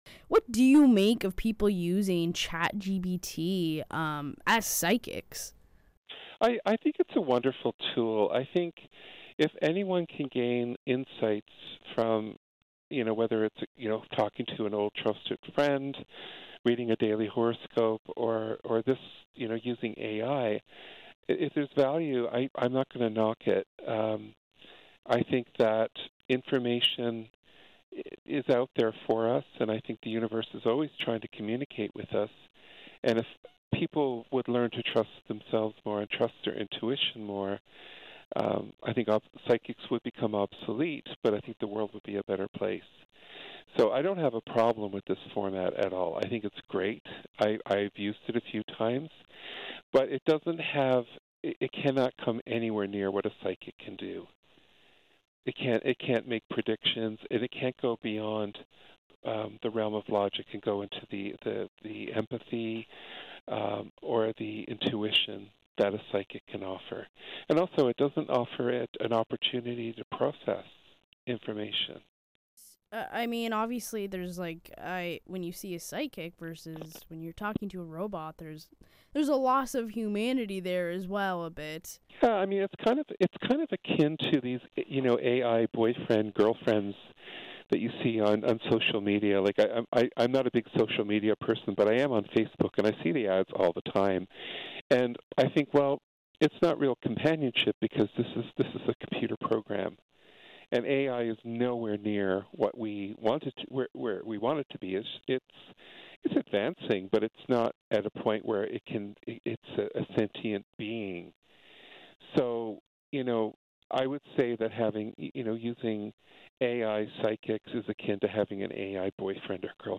cbc-interview.mp3